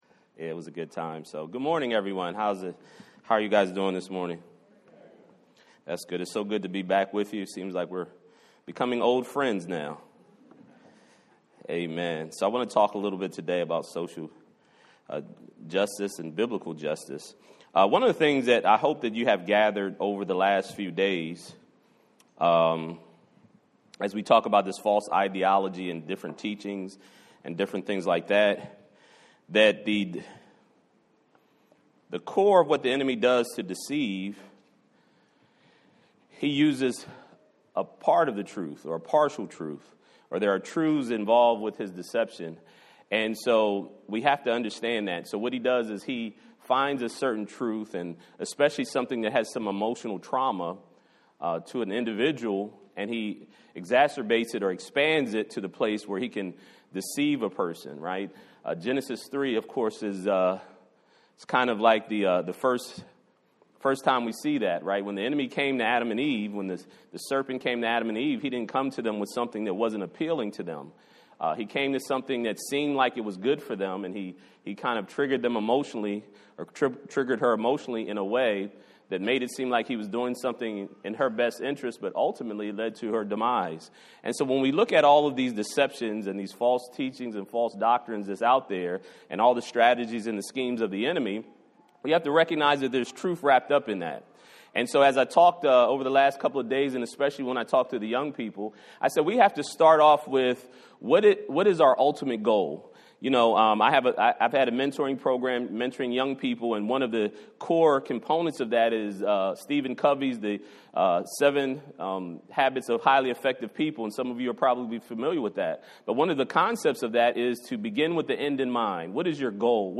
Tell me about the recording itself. Apologetics Conference 2022 – What is Biblical Justice